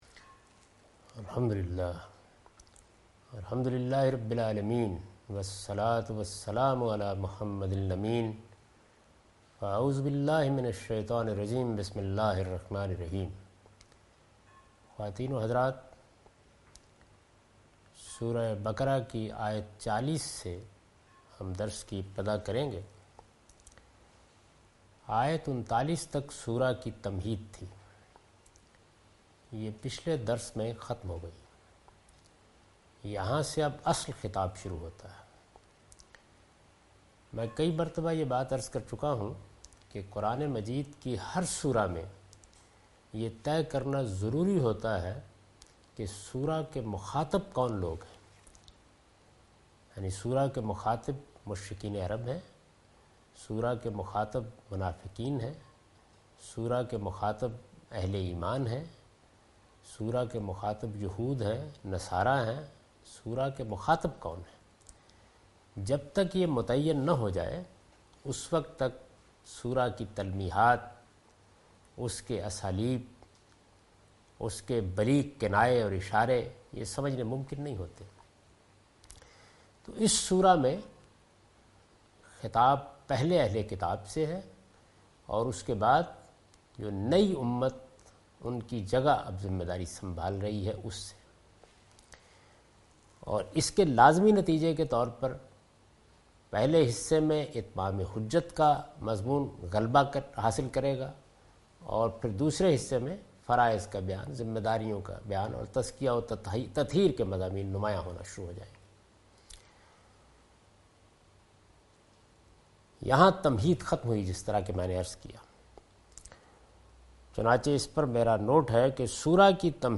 Surah Al-Baqarah - A lecture of Tafseer-ul-Quran – Al-Bayan by Javed Ahmad Ghamidi. Commentary and explanation of verse 38,39,40,41 and 42 (Lecture recorded on 9th May 2013).